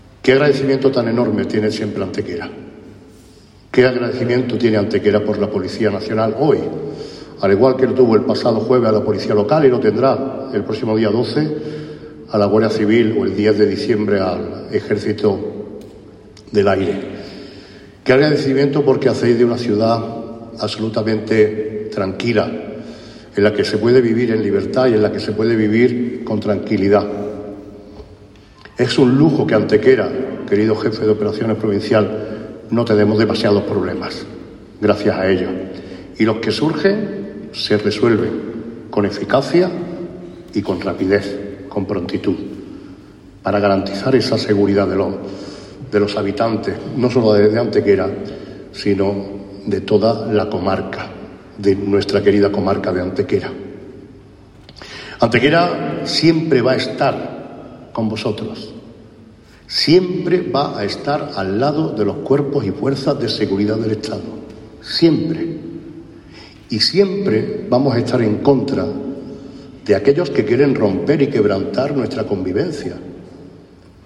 Manolo Barón ensalzó, durante su discurso, la labor de los agentes y responsables de la Policía Nacional en nuestra ciudad respecto a su servicio hacia el bienestar y la convivencia de los ciudadanos.
Cortes de voz